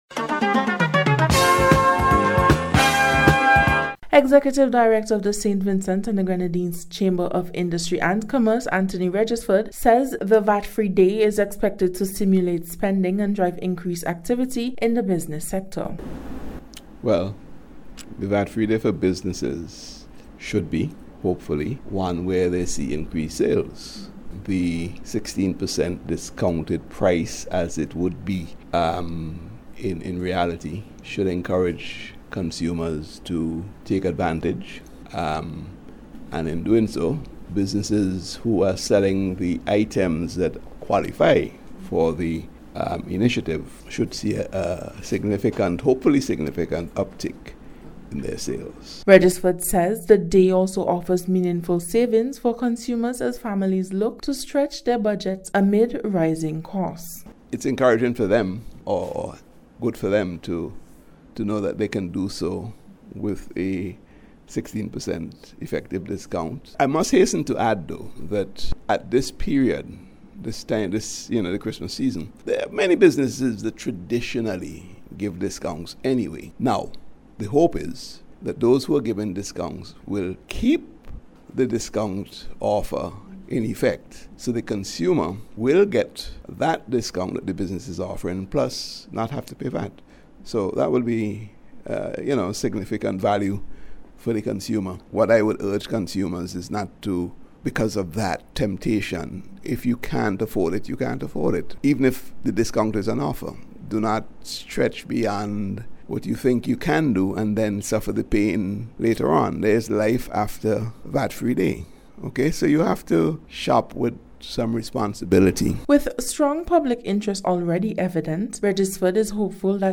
CHAMBER-VAT-FREE-DAY-REPORT.mp3